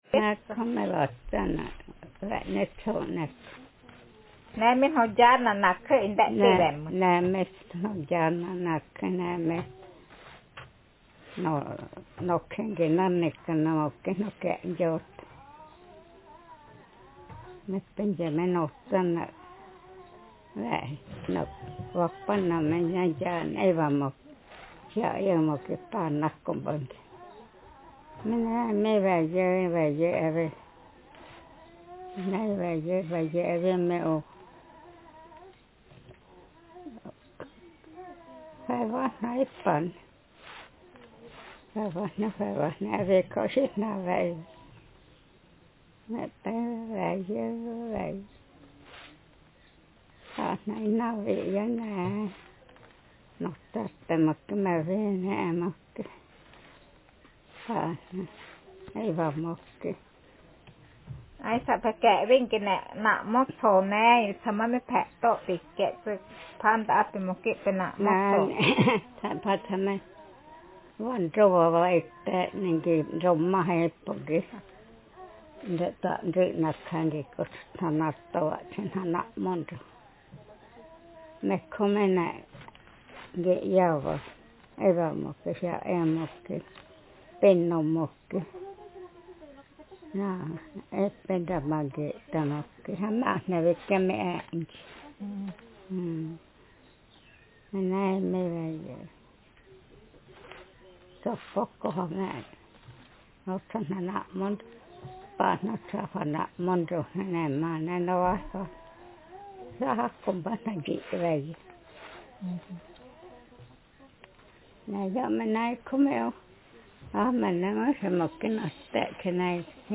Speaker sexf/f
Text genrepersonal narrative